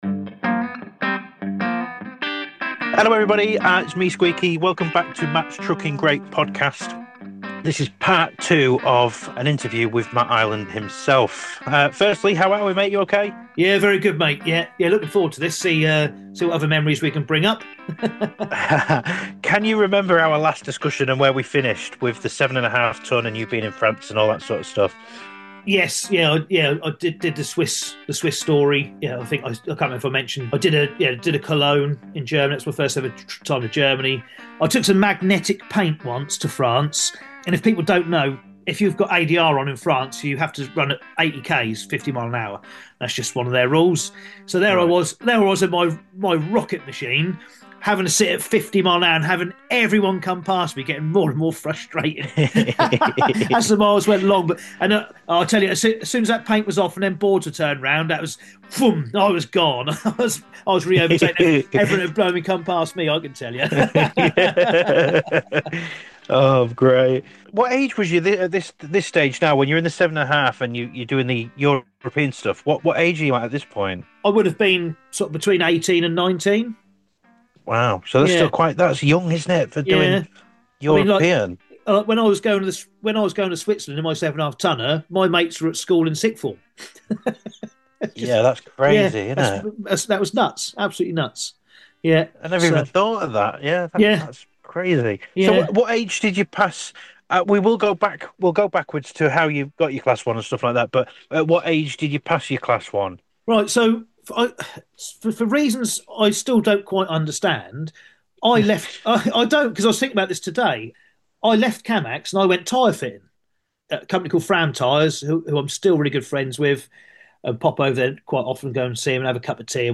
This is the podcast for truck drivers, hosted by and featuring interviews from people in the industry.